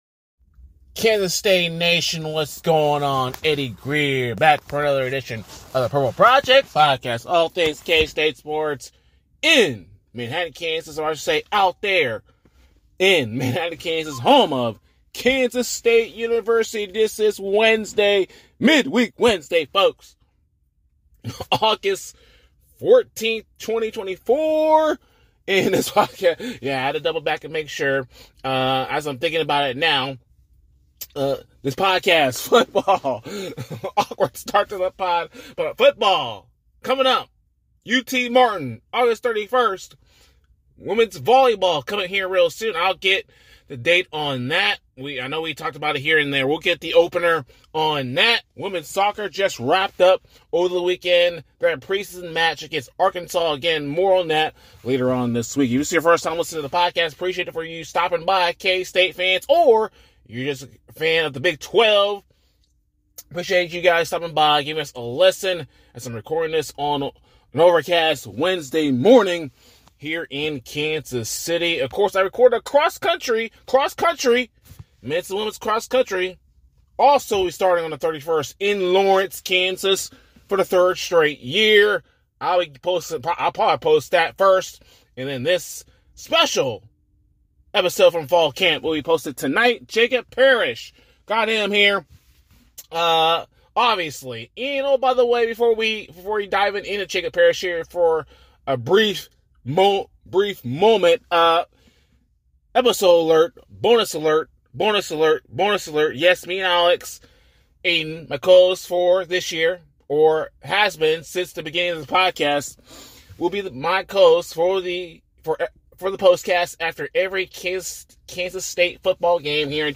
spoke to the media during the Wildcats Fall Camp in preparation for the 2024 season!